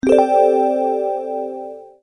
military.wav